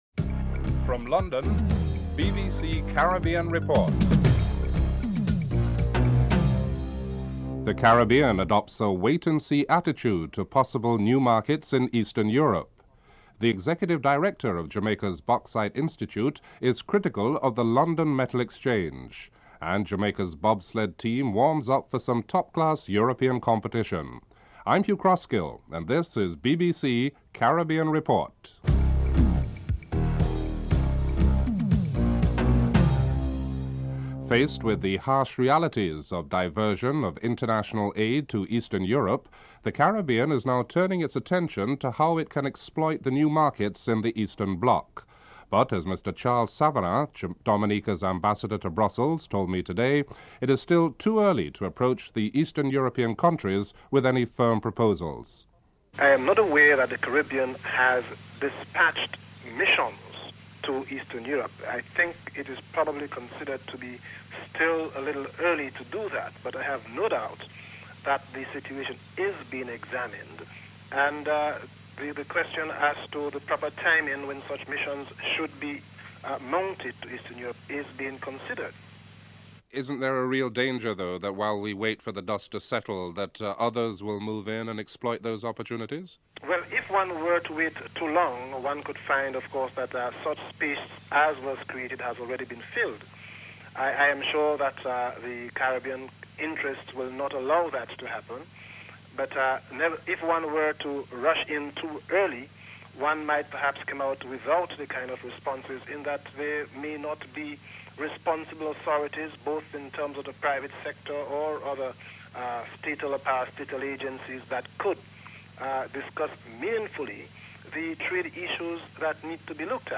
2. Caribbean countries and the new trade opportunities in Eastern Europe. Interview with Charles Savarin, Dominica's Ambassador to Brussels (00:33-03:43)